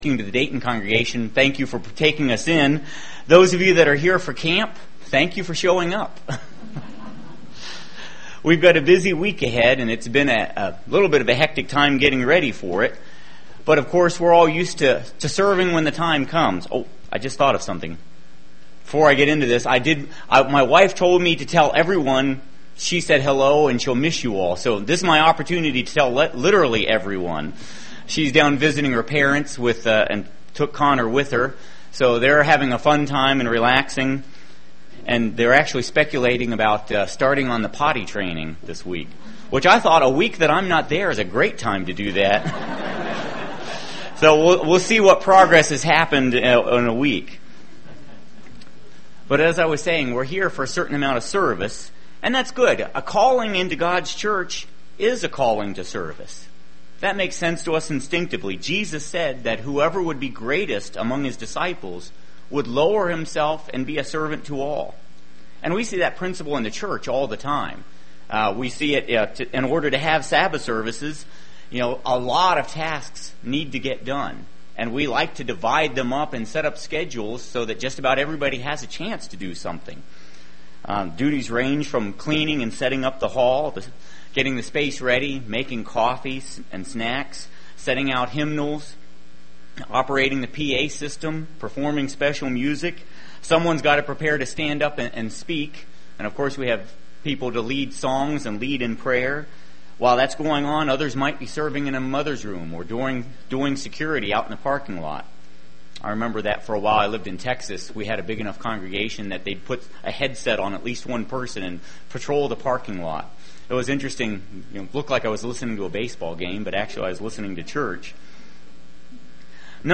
This message was given on the Sabbath prior to camp.